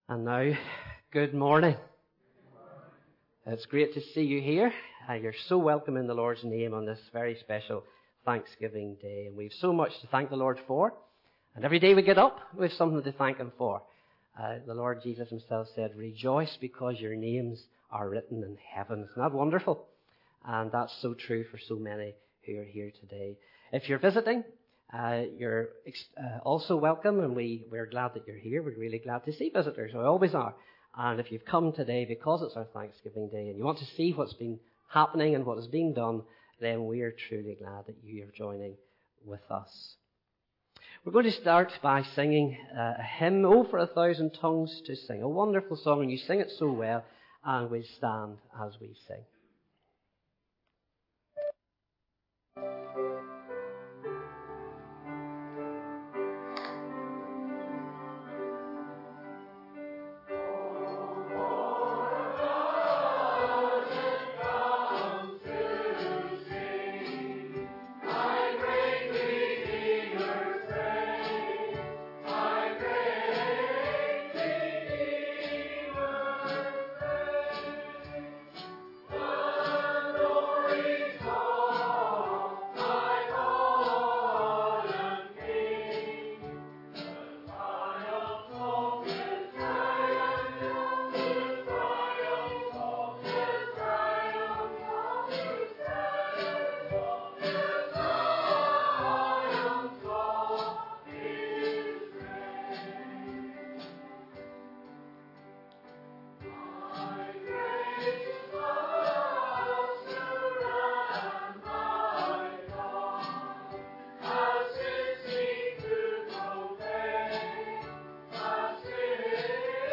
Thanksgiving Service – Sunday 02nd October 2022 AM – Monkstown Baptist Church